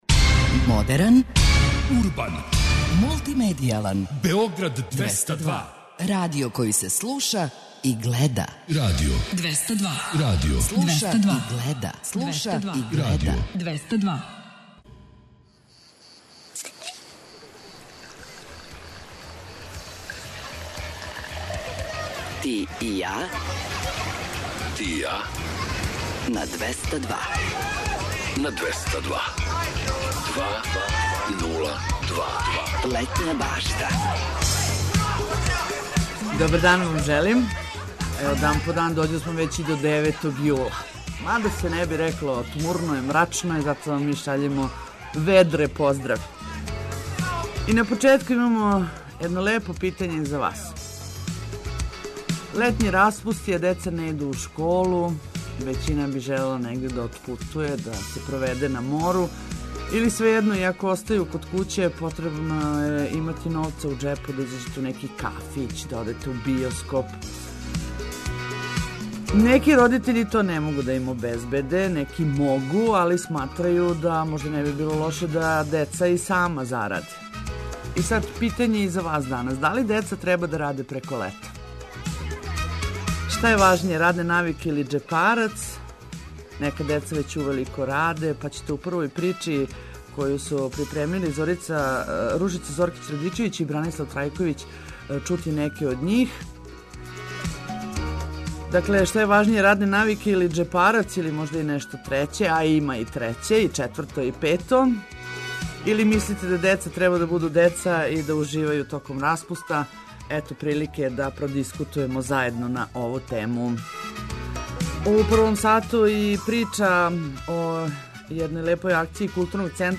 Снимали смо их „раном зором" - кад крећу на поља кукуруза, и касно поподне, у радионицама.